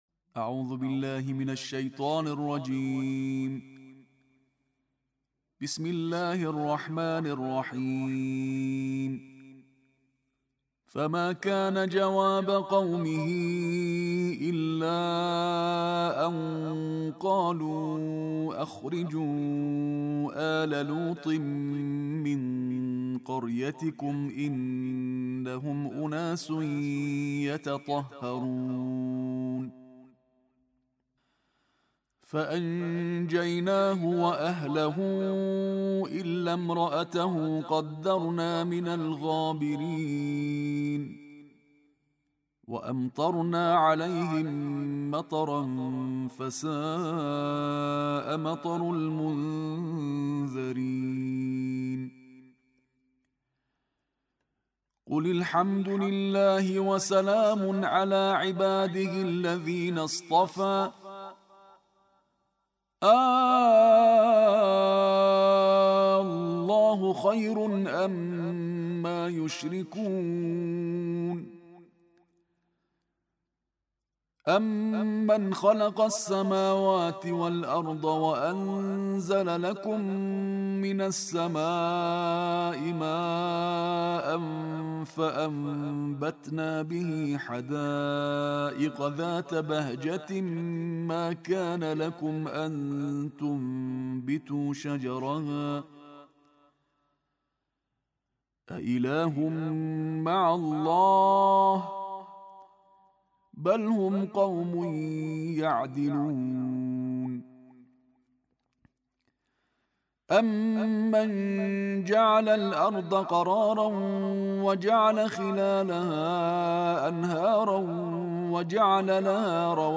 تلاوت ترتیل جزء بیستم قرآن کریم در بیستمین روز از ماه رمضان با صدای قاری بین‌المللی کشورمان منتشر می‌شود.
ترتیل جز 20 قرآن
این تلاوت طی سال‌های 1399 و 1400 در استودیوی شورای عالی قرآن ضبط شده و برای اولین بار است که از یک رسانه منتشر می‌شود.